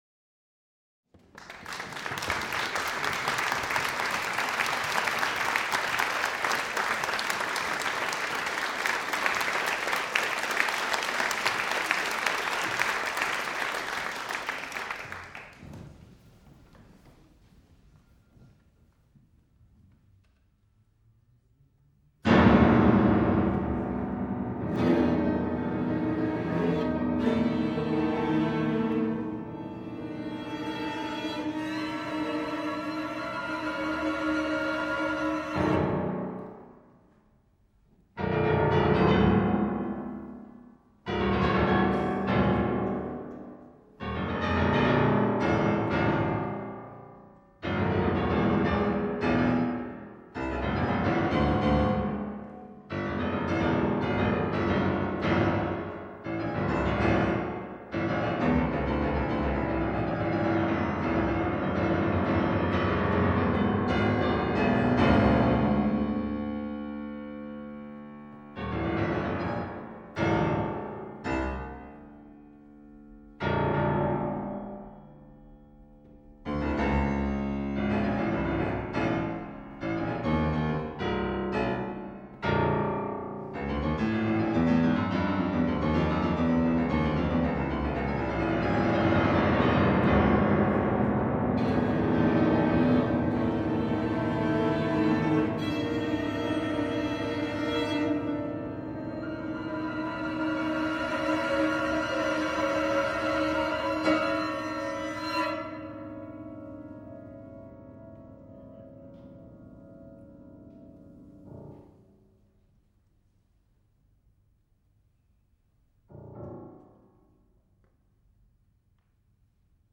violon, alto, violoncelle et piano